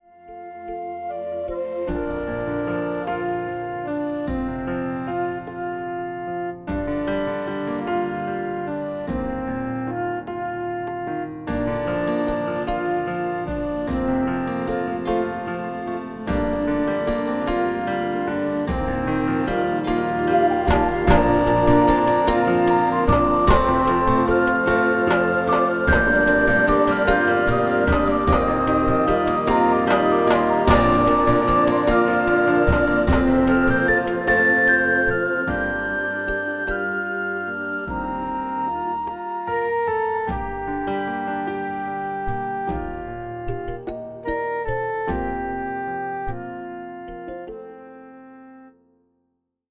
Musically, the show has lots of African rhythms and instrumentation.
Glow Brightly (Backing Only Snippet) Tuesday, 11 April 2017 04:20:23